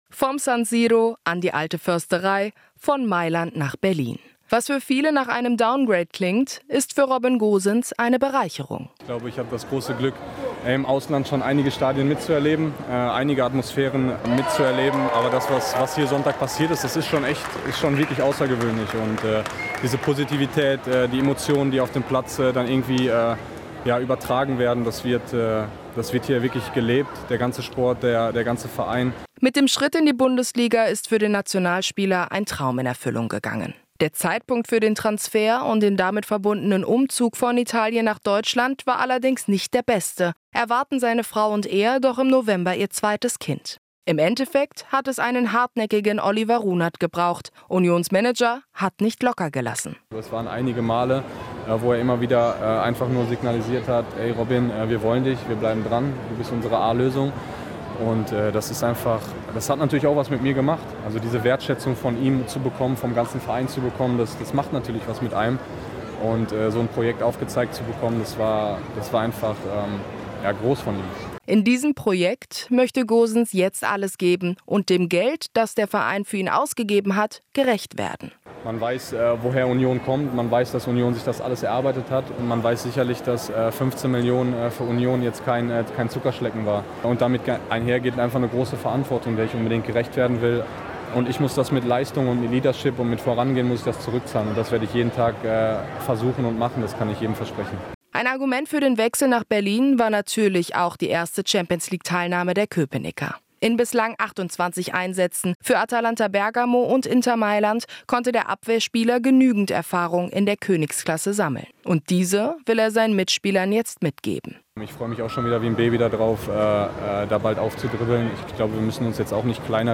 Inforadio Nachrichten, 01.08.2023, 15:00 Uhr - 01.08.2023